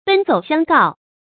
注音：ㄅㄣ ㄗㄡˇ ㄒㄧㄤˋ ㄍㄠˋ
奔走相告的讀法